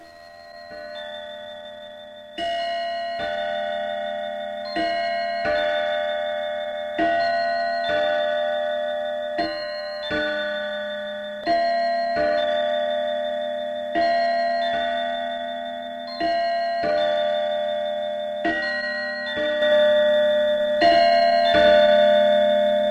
铃声 " 铃声1
描述：简短的风铃叮当声。
标签： 开朗 风铃 二NG 涂鸦 叮当
声道立体声